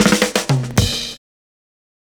112FILLS11.wav